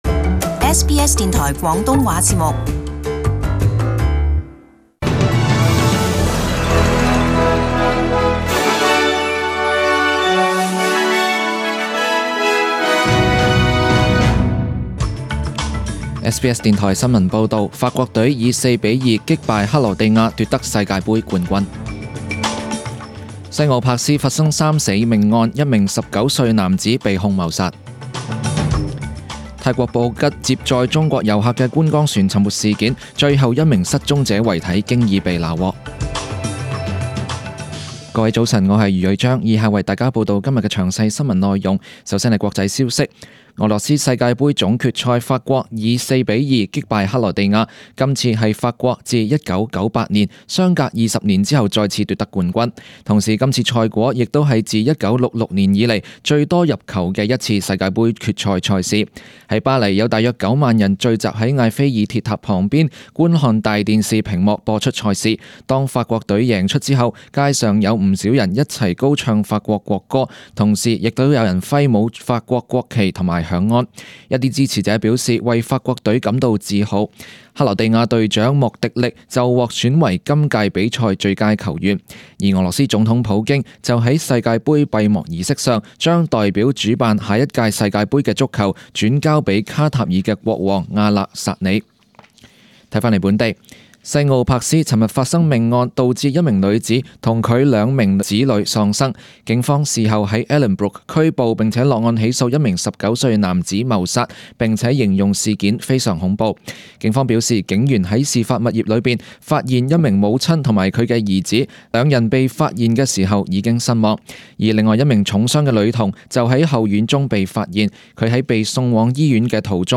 SBS中文新闻 （七月十六日）
请收听本台为大家准备的详尽早晨新闻。